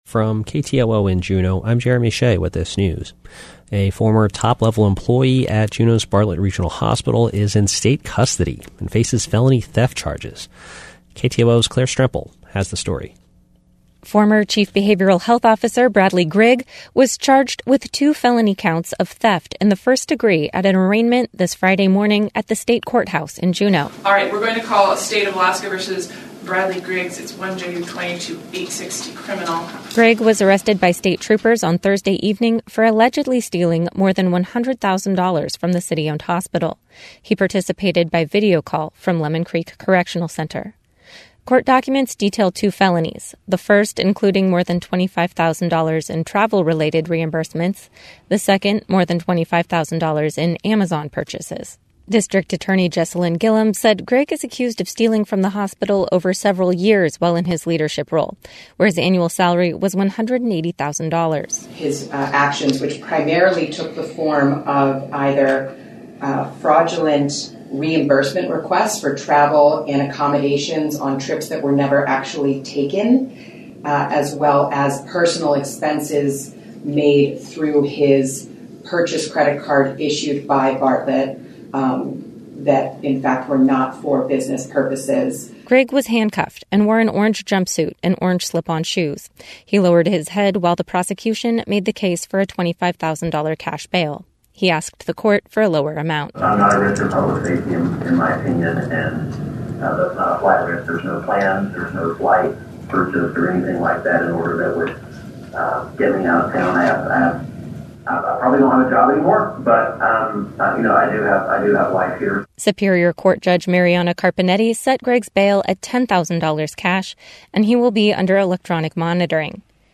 Newscast – Monday, Aug. 29, 2022